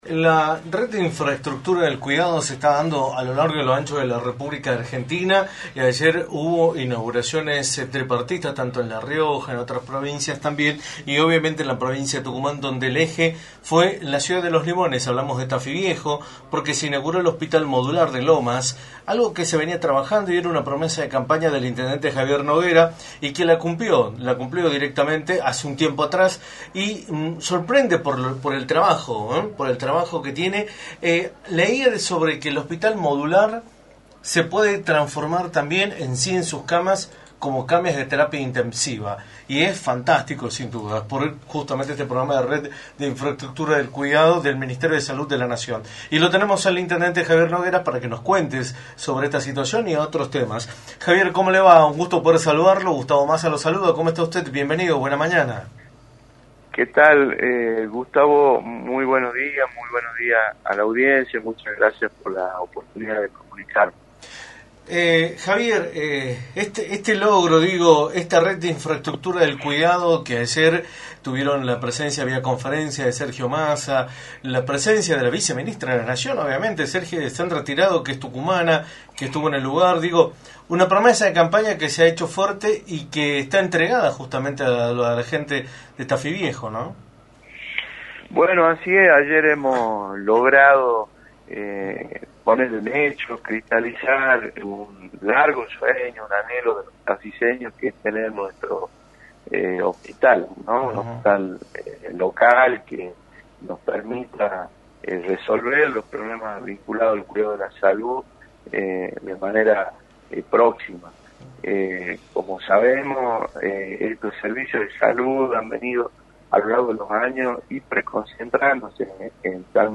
Javier Noguera, Intendente de Tafí Viejo y Legislador electo, remarcó en Radio del Plata Tucumán, por la 93.9, cuáles fueron las repercusiones de la inauguración del Hospital Modular de Lomas de Tafí y analizó la situación política del país.